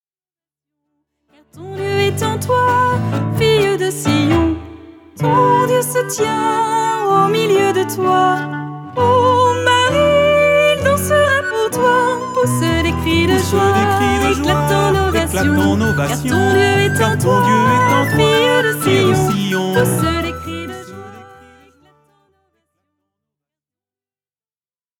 Des chants de m�ditation